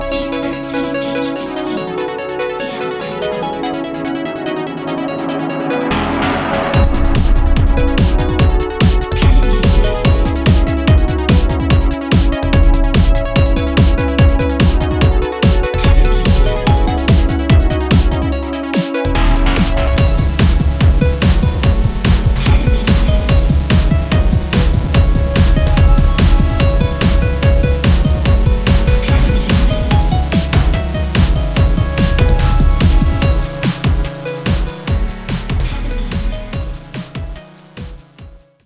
• Genre : hard techno, hard bounce, dark techno, dark trance